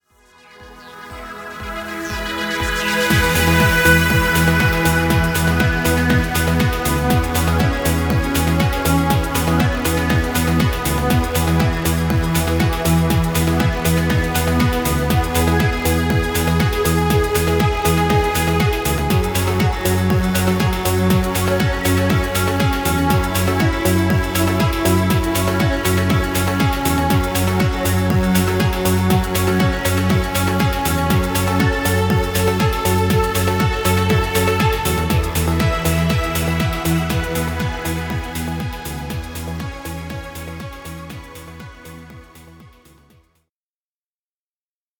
Dance muisc. Background music Royalty Free.